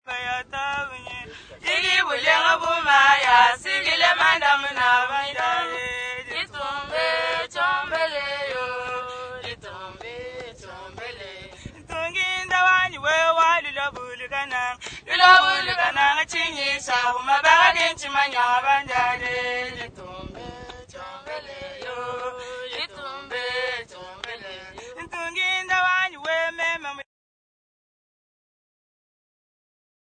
4 Kaski women
Folk music--Africa
Field recordings
Africa Zambia Not specified f-za
sound recording-musical